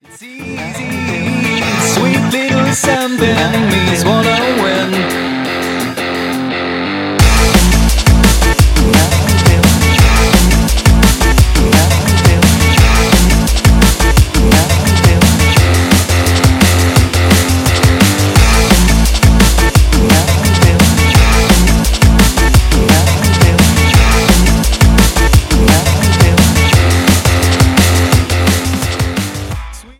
DNB mix